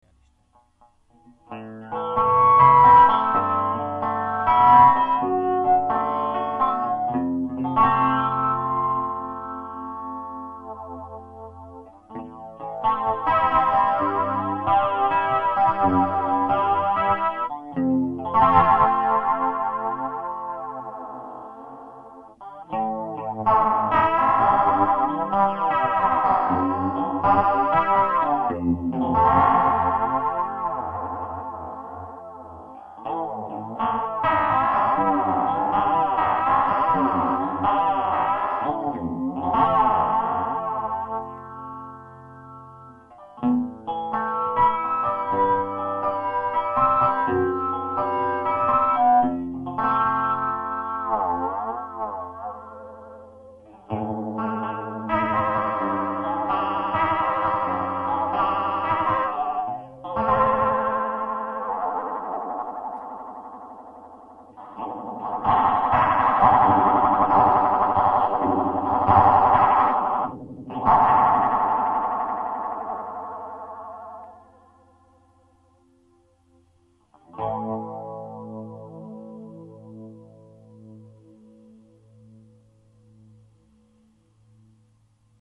hang: flanger chorus